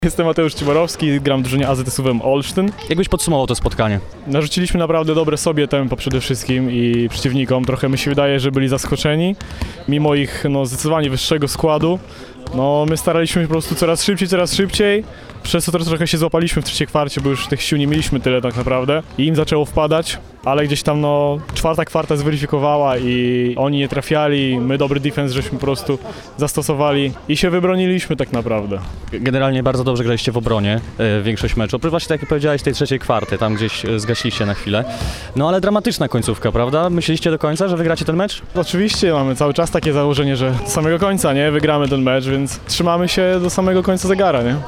mówił po meczu